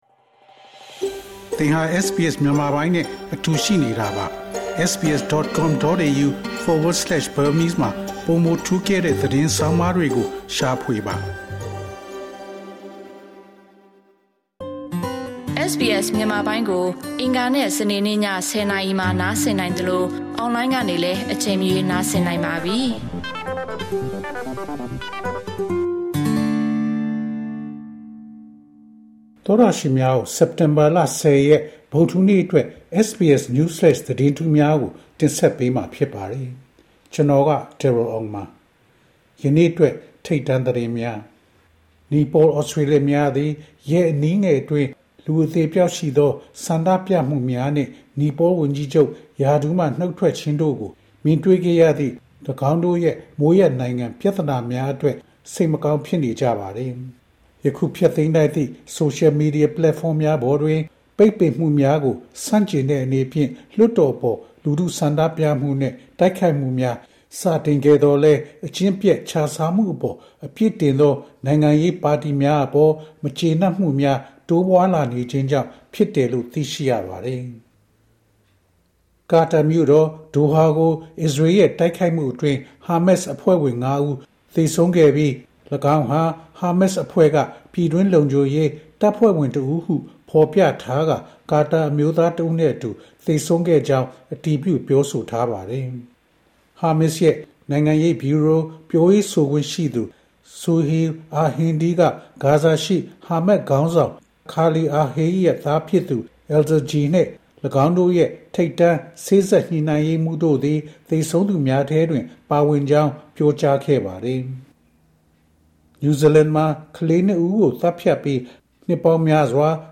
SBS မြန်မာ ၂၀၂၅ ခုနှစ် စက်တင်ဘာလ ၁၀ ရက် နေ့အတွက် News Flash သတင်းများ။